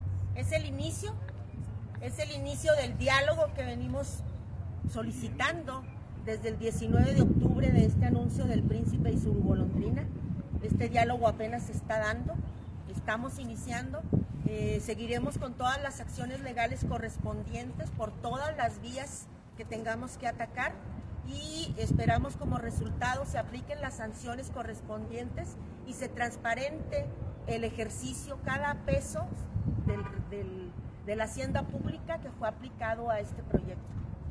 Al levantar el plantón , los artistas dirigieron un mensaje a la ciudadanía.